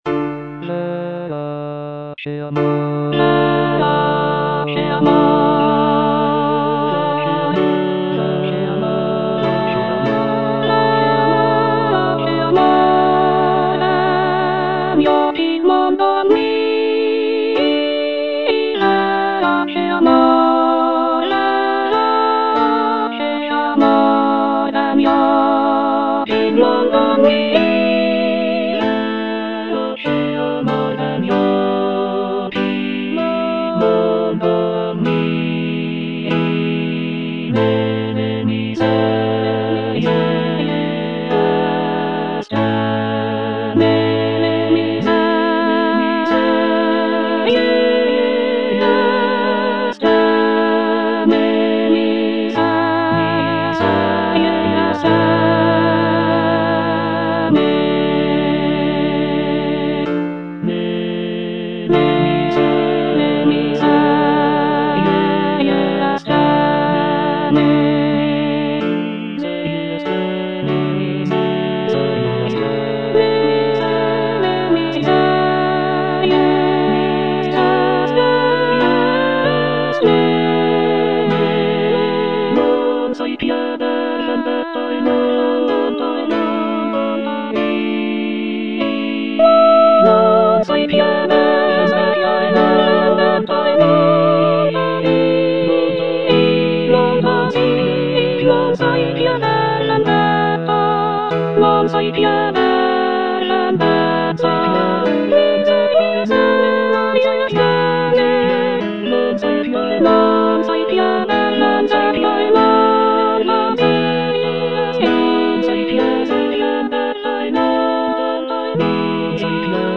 soprano I) (Emphasised voice and other voices) Ads stop